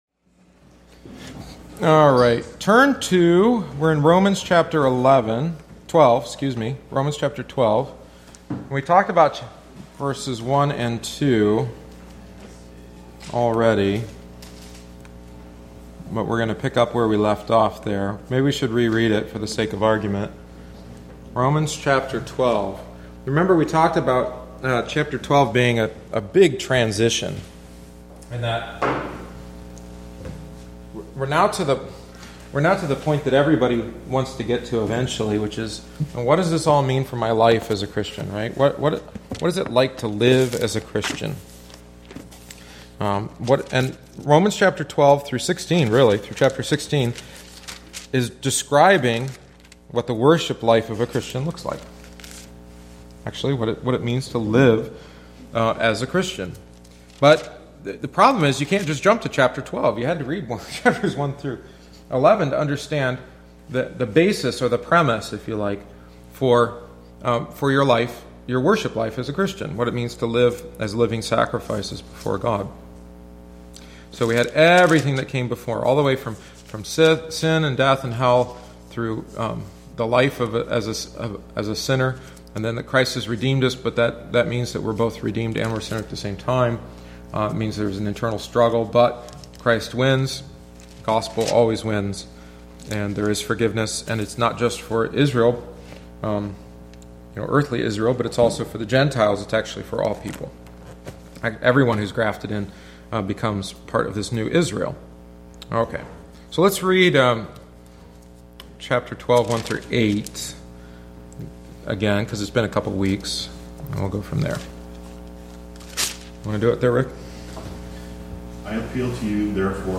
The following is the twenty-eighth week’s lesson. In chapter 12, Paul begins to address our lives in Christ.